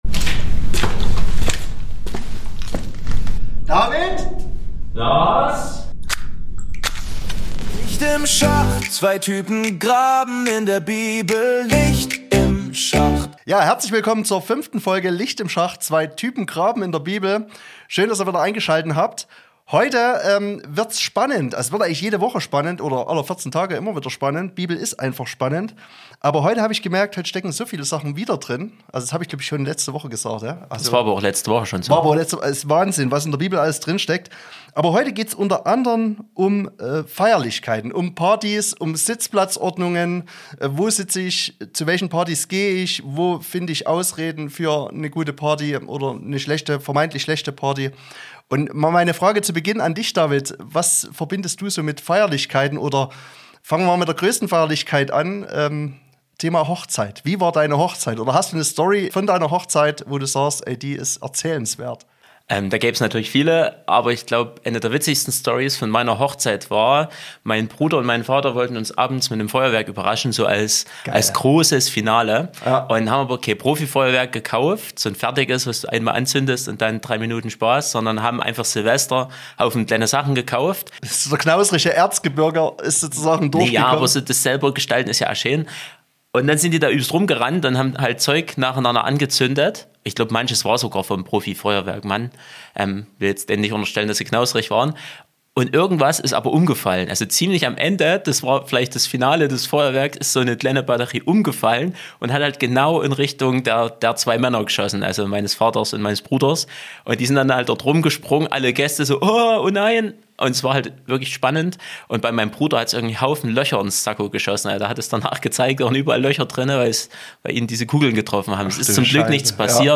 Folge 5 - Wenn der Hund die Hausaufgaben gefressen hat ... ~ Licht im Schacht - zwei Typen graben in der Bibel Podcast